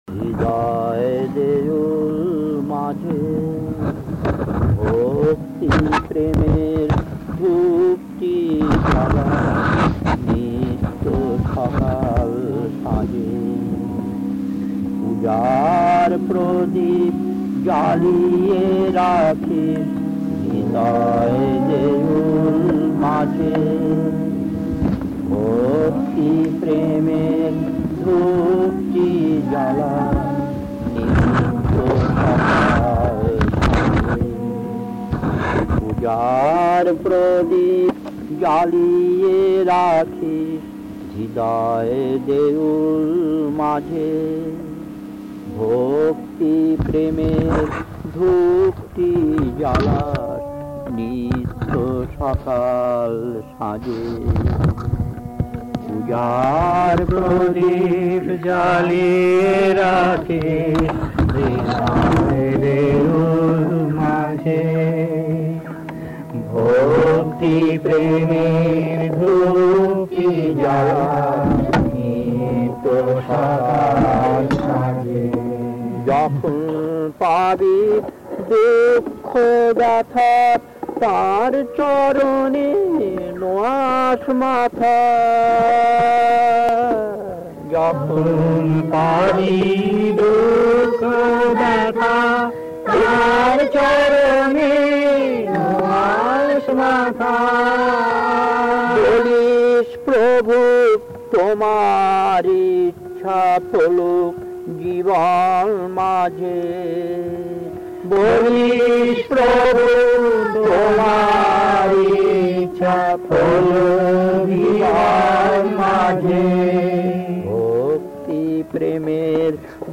Kirtan D8-2 1.